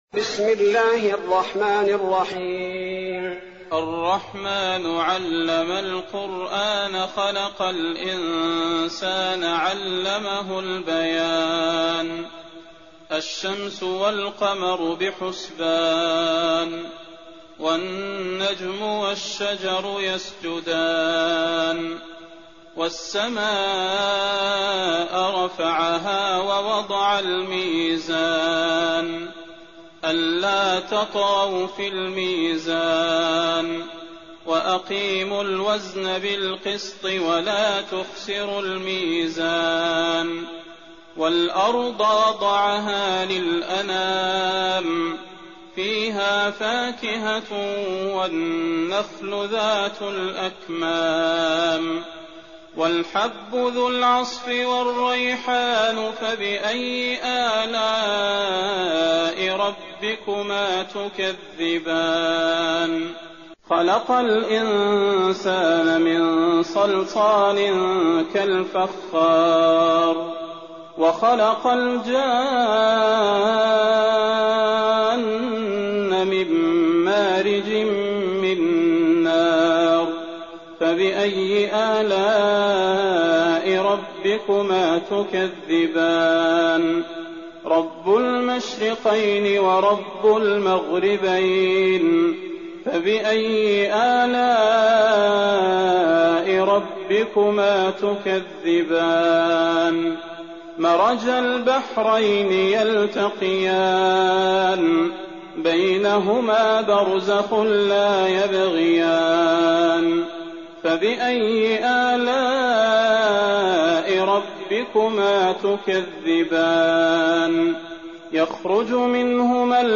تراويح ليلة 26 رمضان 1419هـ من سورة الرحمن الى سورة الحديد Taraweeh 26th night Ramadan 1419H from Surah Ar-Rahmaan to Al-Hadid > تراويح الحرم النبوي عام 1419 🕌 > التراويح - تلاوات الحرمين